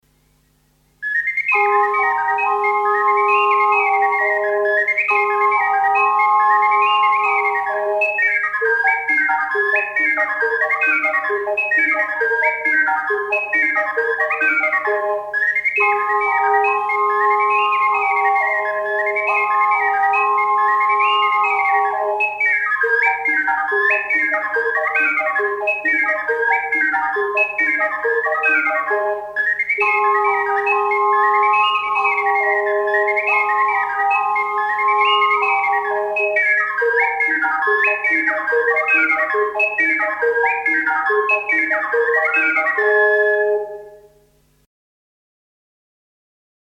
Balli popolari emiliani in .mp3
in incisione multipla con 5 ocarine